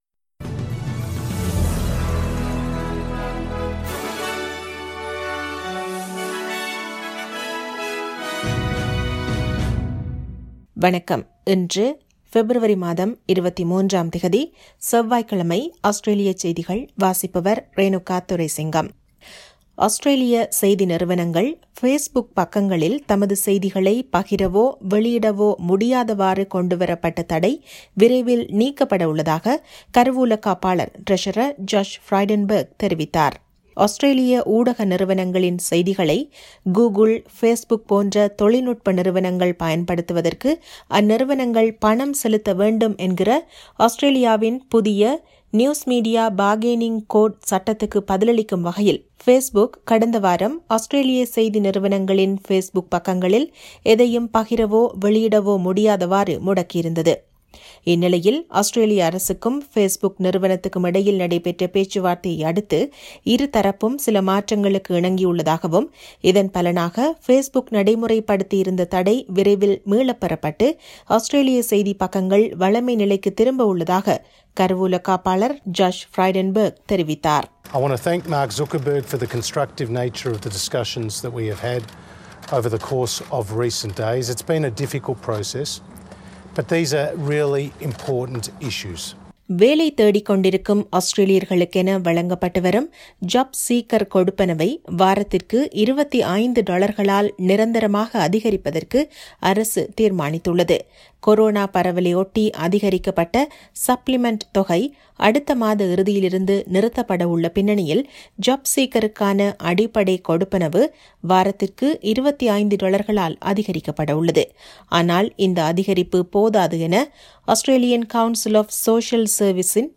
Australian news bulletin for Tuesday 23 February 2021.